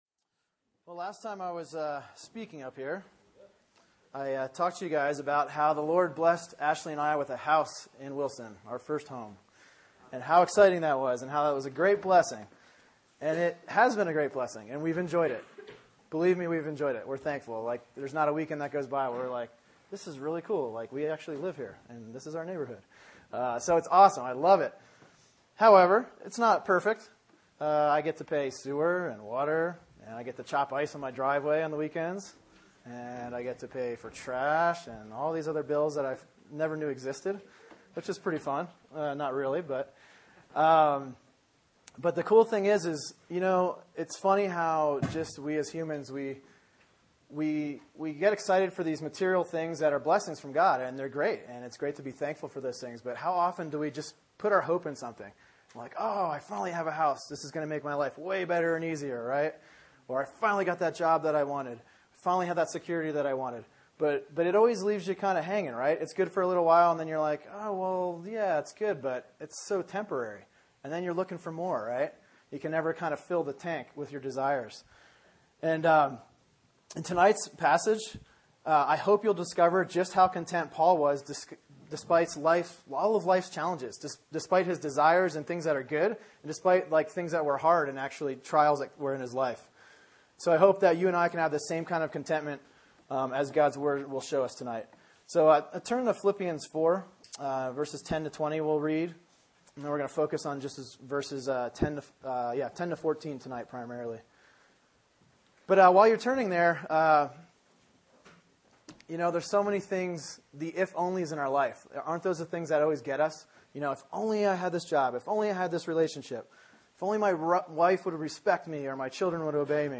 Sermon: Philippians 4:10-14 “Finding Contentment” | Cornerstone Church - Jackson Hole